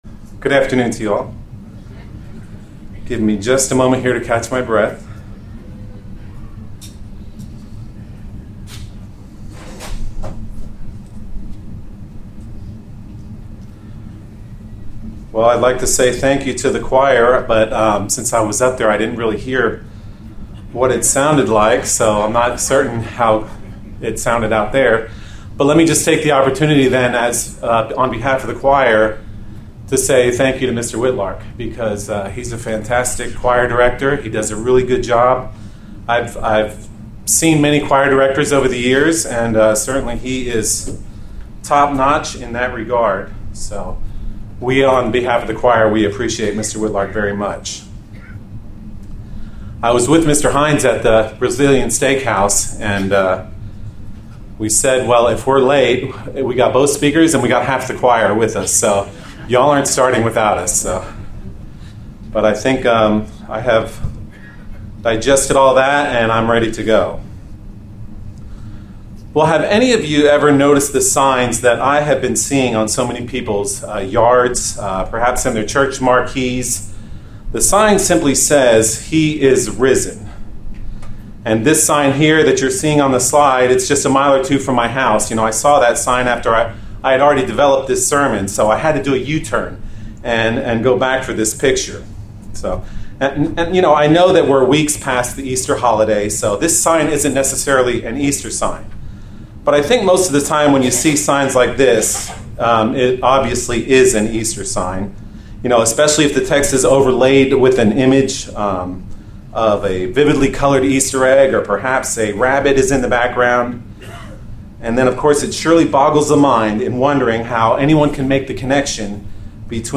The traditional view of Jesus Christ is to either focus on his human birth or his crucifixion. In this sermon we will go beyond his Resurrection to see what he's doing right now, how he relates to us and how he wants us to view him from the vantage point of his current reality as the risen Son of God at God the Father's right hand.